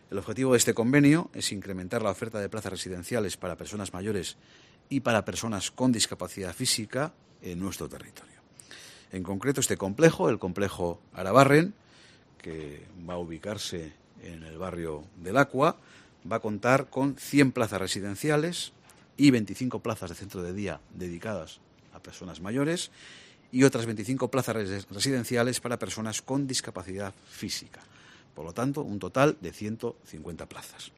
Ramiro González, Diputado General de Álava